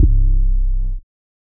muffled 808 2.wav